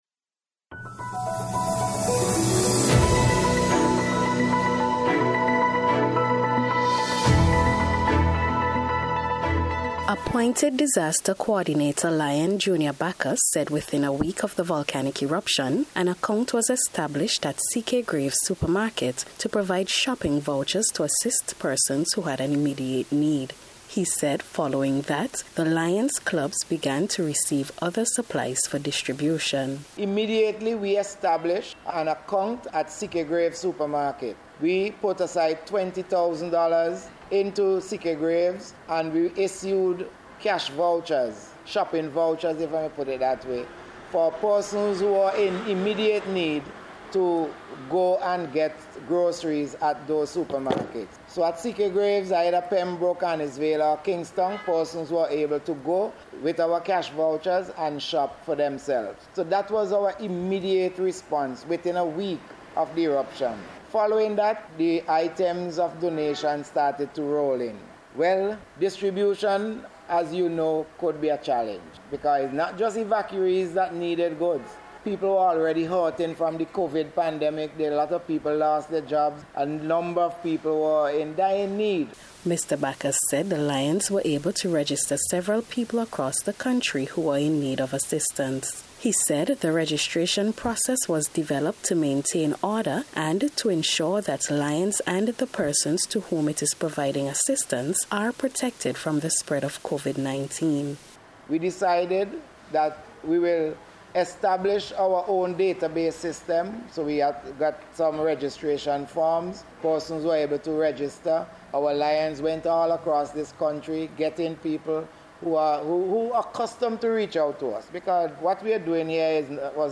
LIONS-RELIEF-REPORT.mp3